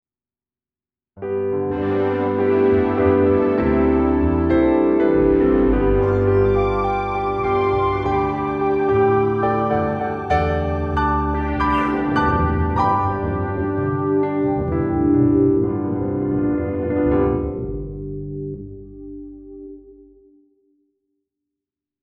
A short musical excerpt I tried some combined sounds on, recorded with Cubase and some processed with the Lexicon Pantheon plugin:
I used the digital output, which sounds great on the Omega converter.